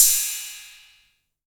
808CY_6_Tape.wav